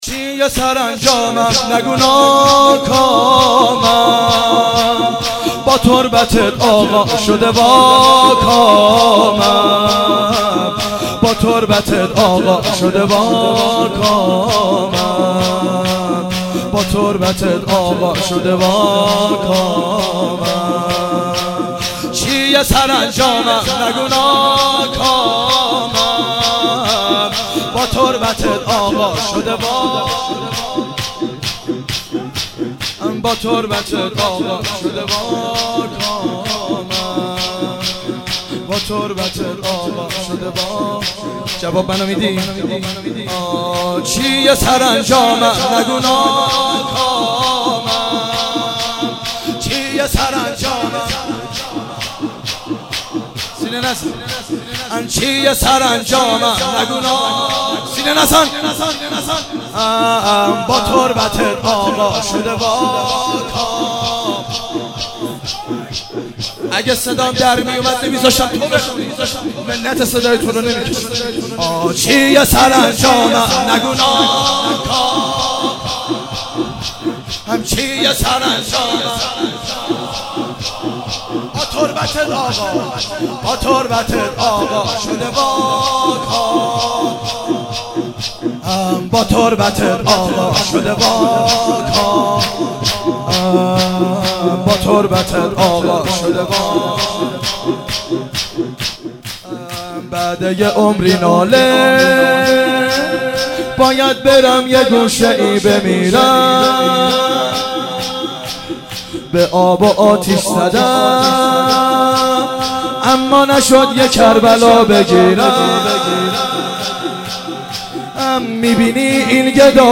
شور . چیه سرانجامم نگو ناکامم .